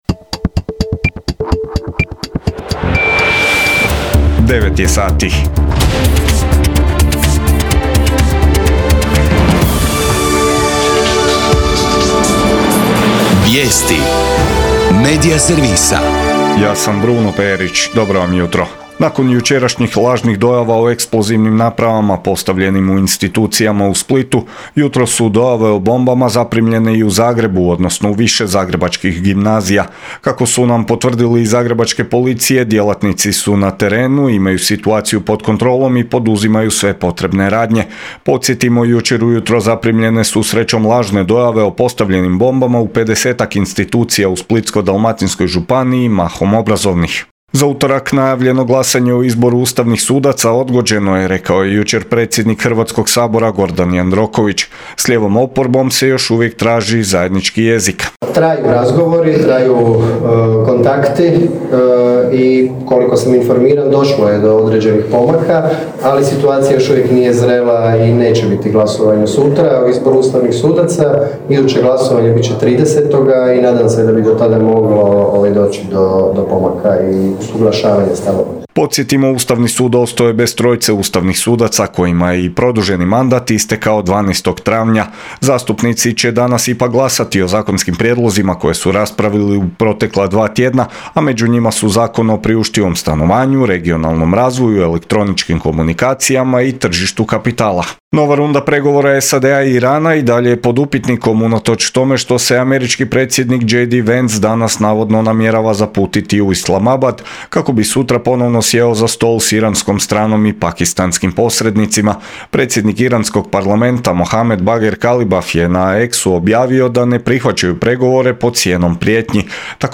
VIJESTI U 9